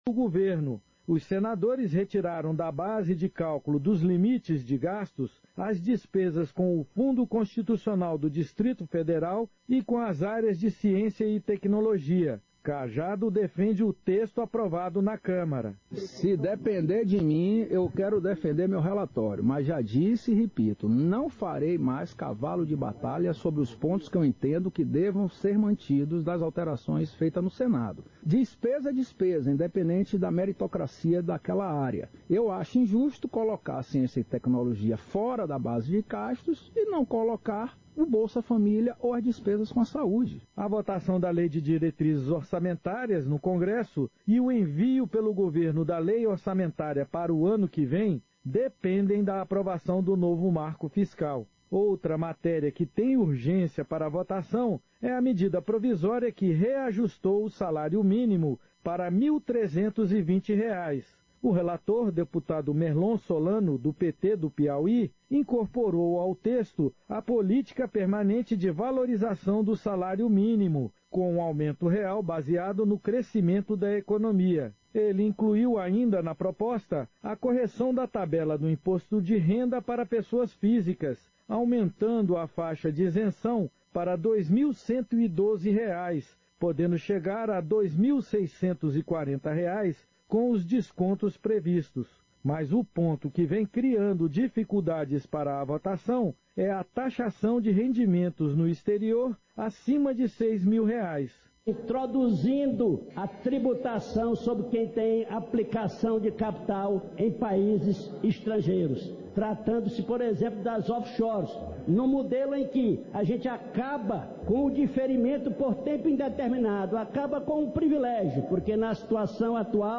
Sessão Ordinária 27/2023